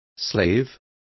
Complete with pronunciation of the translation of slave.